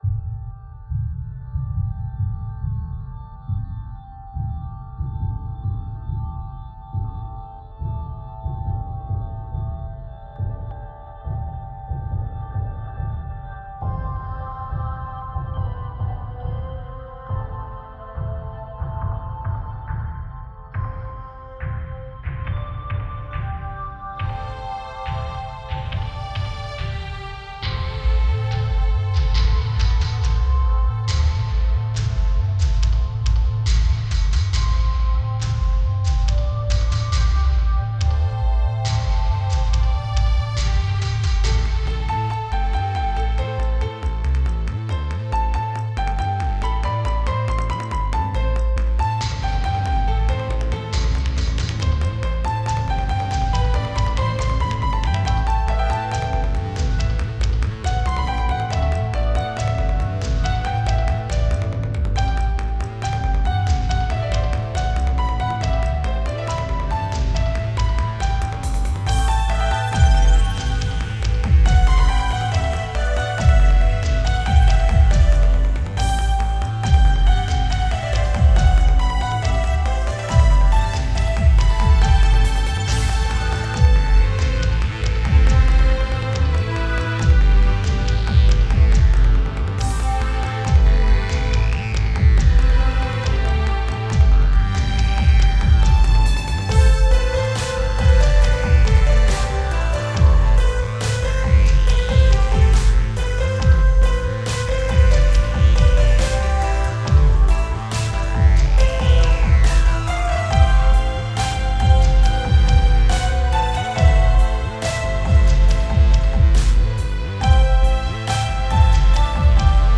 free video game ReMix album